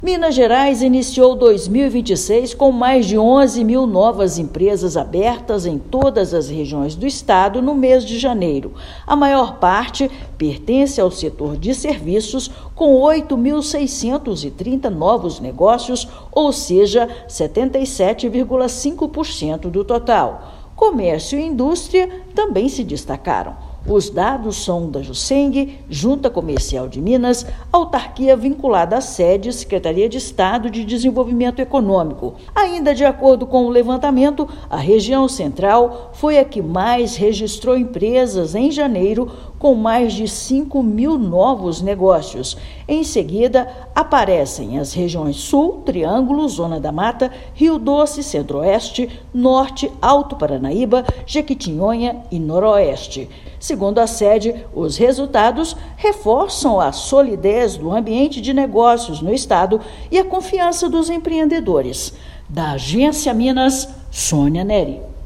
Setor de serviços concentra 77,5% dos novos empreendimentos formalizados no primeiro mês do ano. Ouça matéria de rádio.